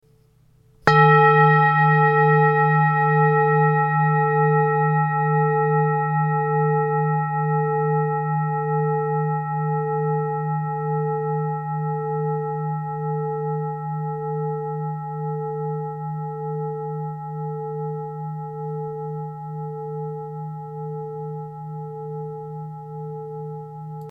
TIBETISCHE THERAPIE KLANGSCHALE - EROS
Grundton: 153,09 Hz
1. Oberton: 441,47 Hz
PLANETENTON EROS (154,57 Hz) - Ton DIS